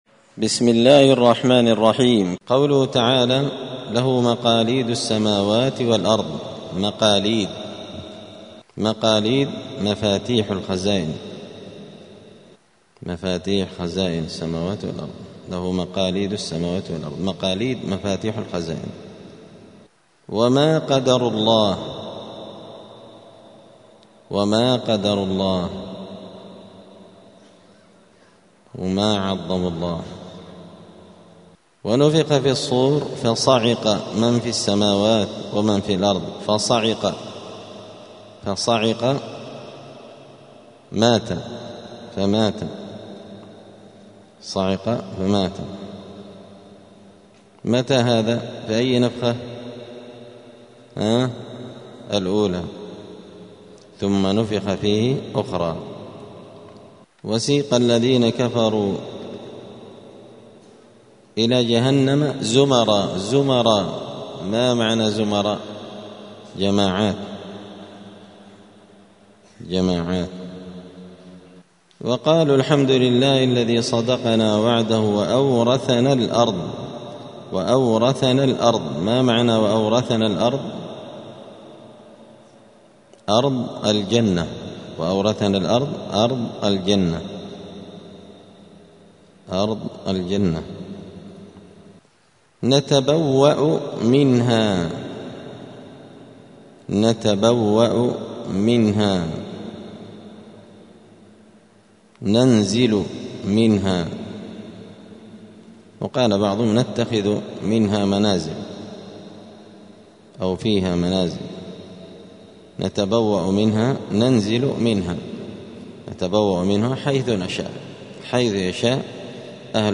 *(جزء الزمر سورة الزمر الدرس 260)*